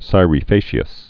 (sīrē fāshē-əs, skērĕkē-äs)